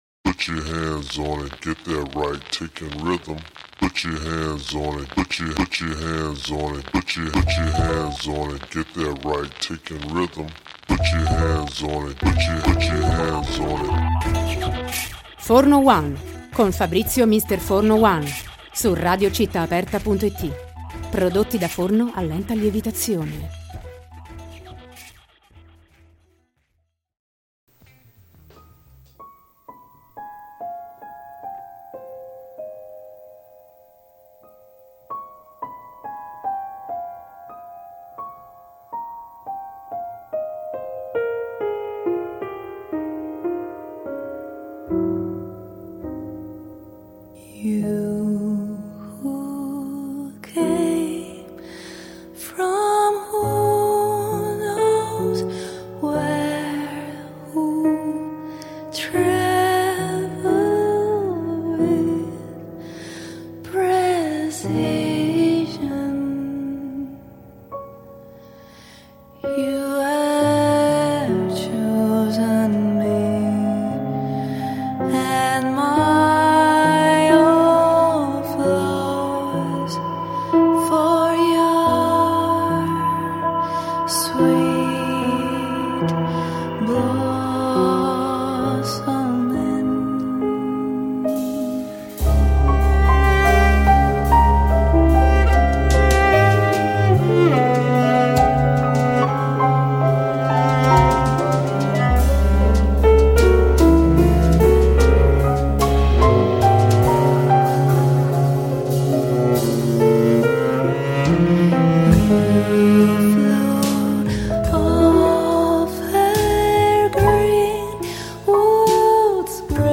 Prometheus: intervista